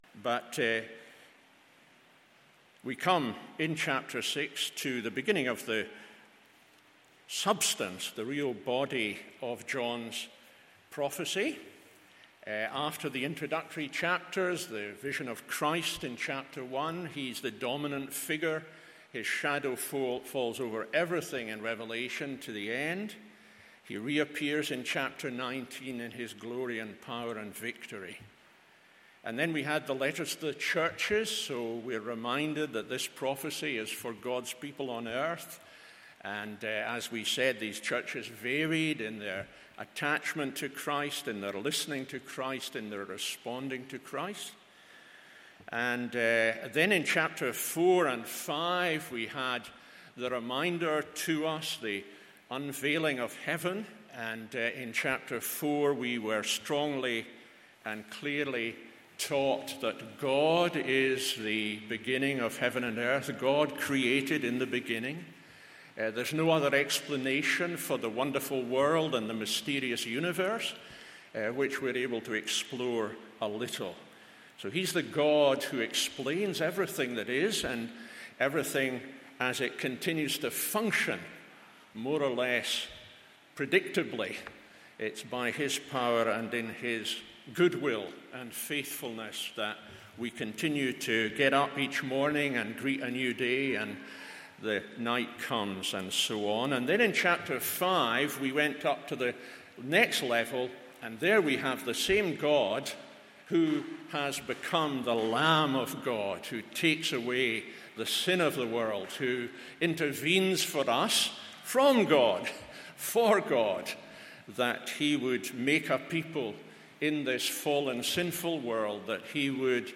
MORNING SERVICE Revelation 6…